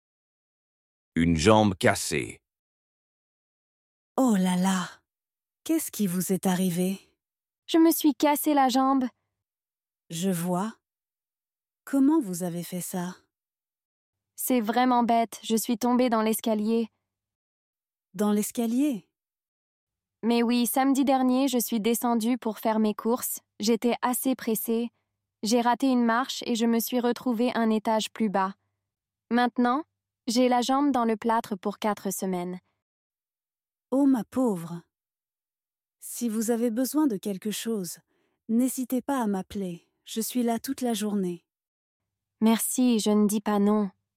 Dialogue en français A2 – Une jambe cassée : conversation simple entre deux amies après une chute. Apprenez à parler des accidents et offrir de l’aide.